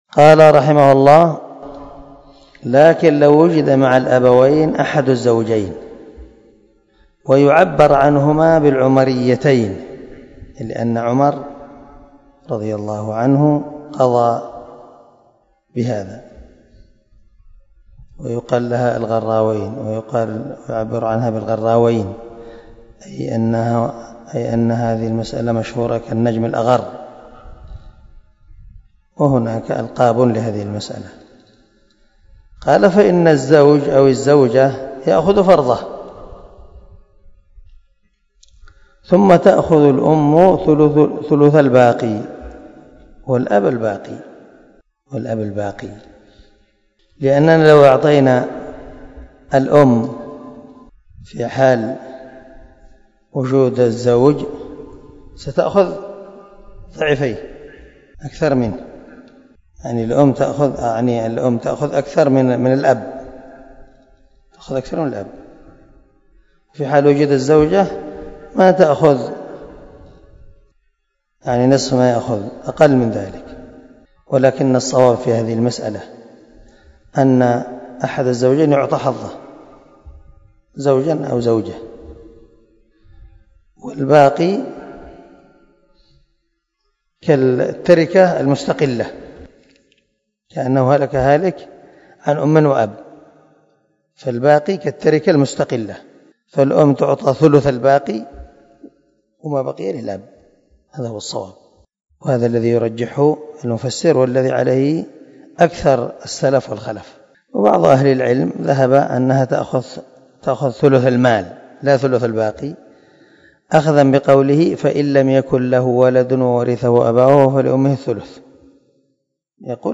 سلسلة_الدروس_العلمية
دار الحديث- المَحاوِلة-